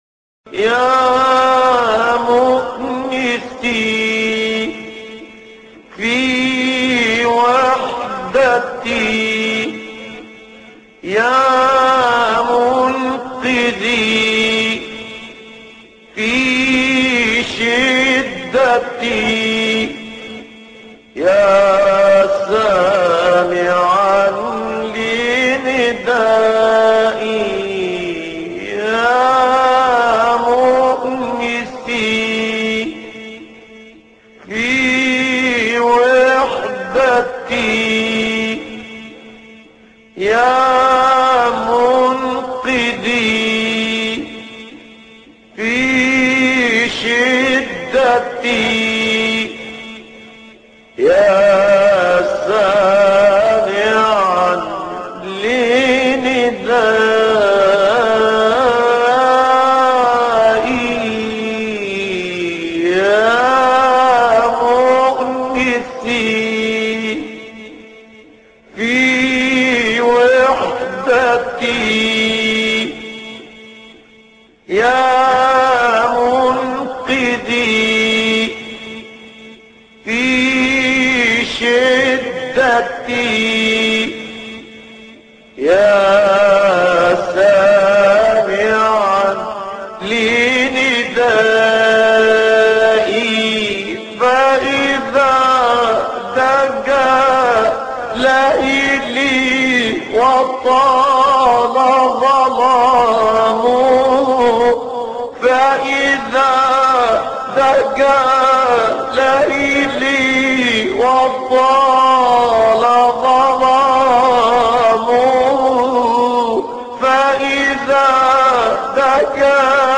موقع نور القرأن | ابتهالات الشيخ نصر الدين طوبار